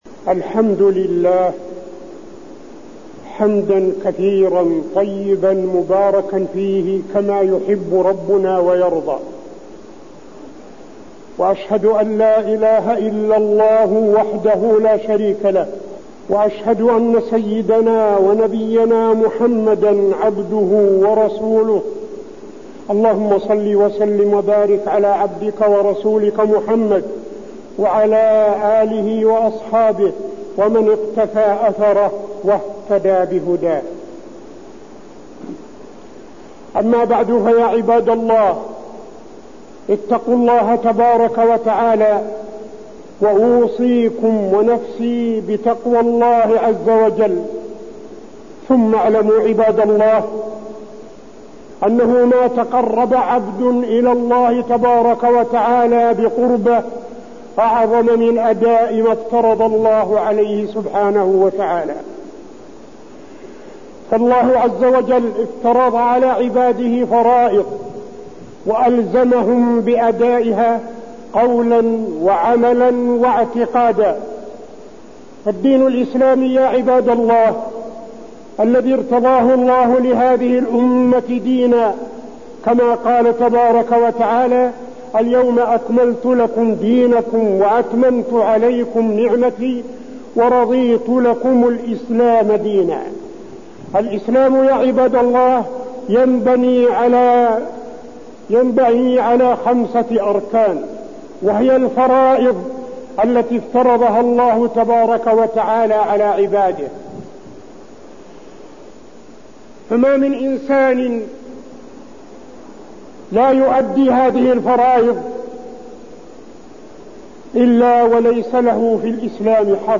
تاريخ النشر ٩ شعبان ١٤٠٦ هـ المكان: المسجد النبوي الشيخ: فضيلة الشيخ عبدالعزيز بن صالح فضيلة الشيخ عبدالعزيز بن صالح بني الإسلام على خمس